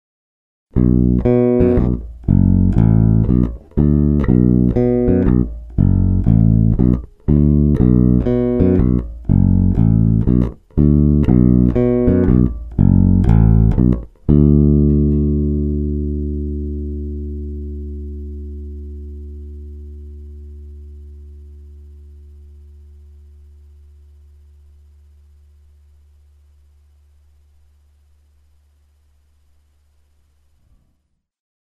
Lakland 55-02 Deluxe, struny Sandberg nehlazená ocel asi měsíc staré, aktivka zapnutá, korekce na střed, hráno prsty.
Kobylový snímač jako singl blíž ke kobyle, aktivka na rovinu